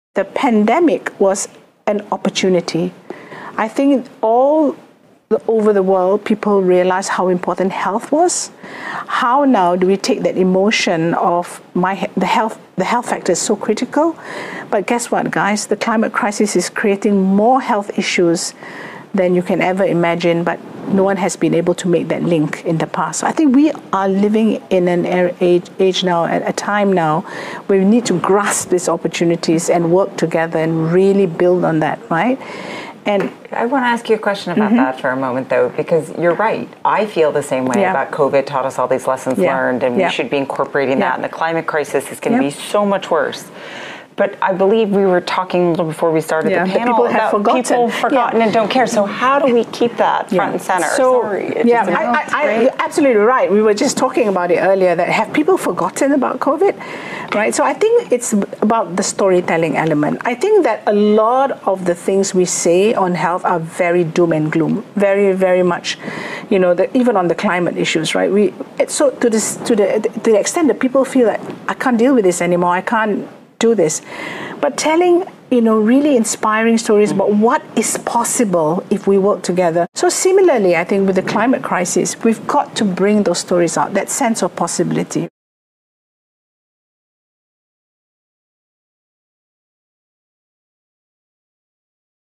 Während einer kürzlichen WEF-Podiumsdiskussion diskutierten Mitglieder von Klaus Schwabs nicht gewählter Organisation Methoden, um die Öffentlichkeit zur Einhaltung unpopulärer grüner Agenda-Politiken zu bewegen.